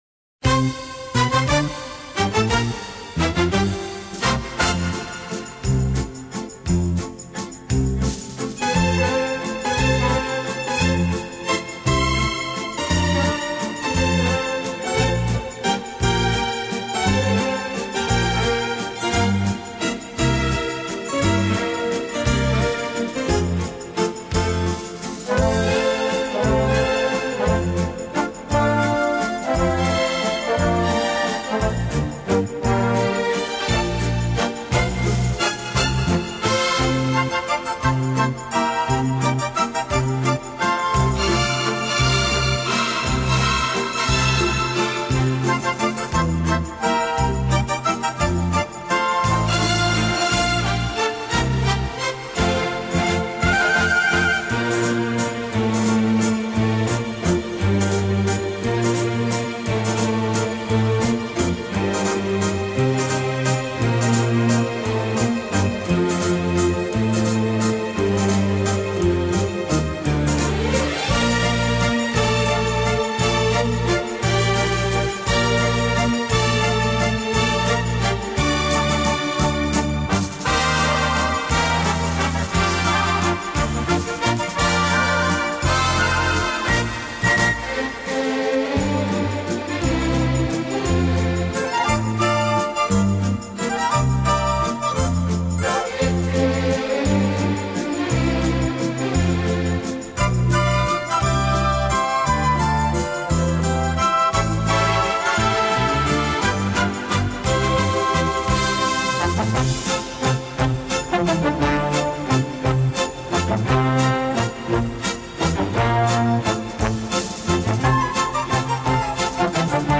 Genre:Dance